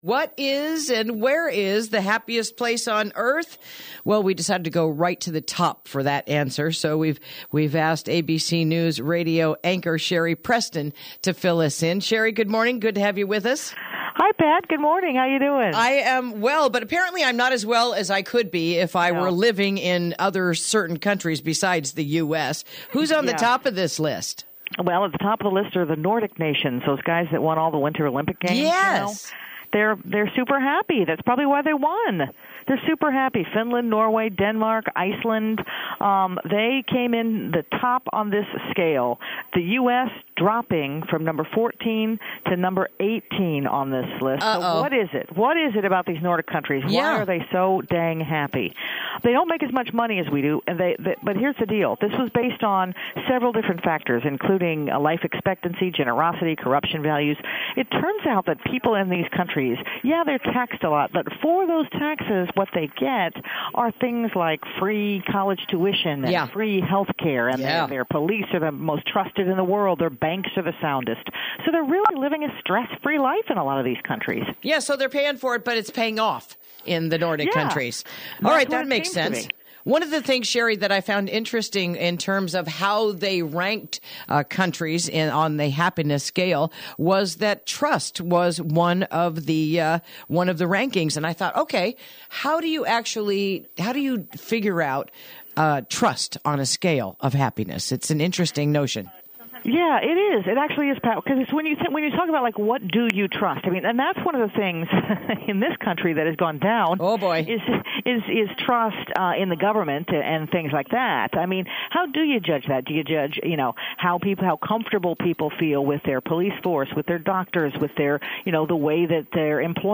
Interview: Turns Out the Happiest Place on Earth Isn't Disneyland | KSRO 103.5FM 96.9FM & 1350AM